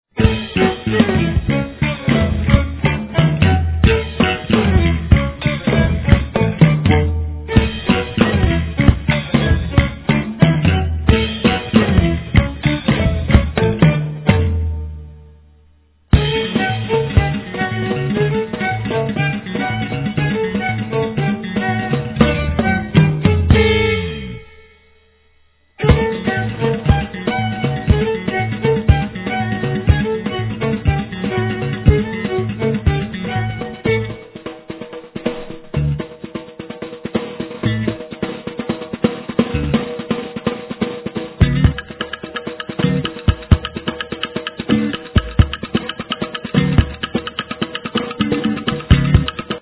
Viola, Vocals
Guitar-bass
Drums